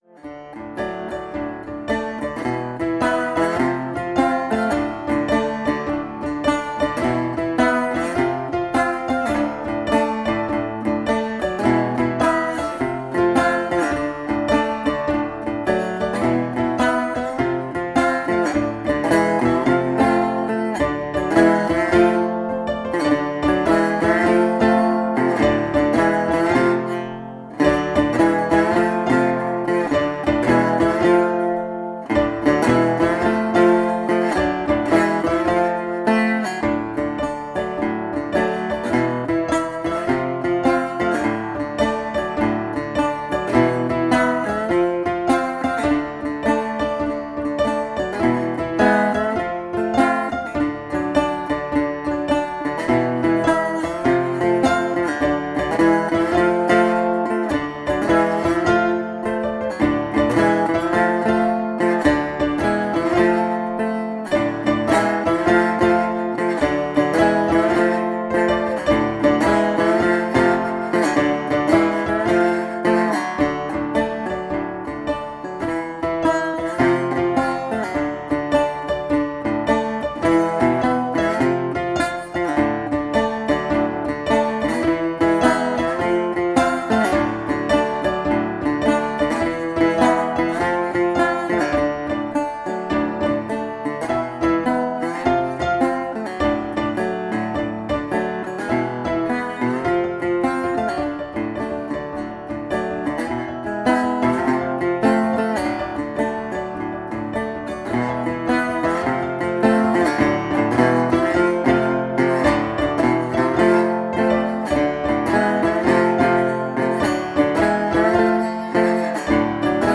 Tempo: 90 bpm / Datum: 01.01.2017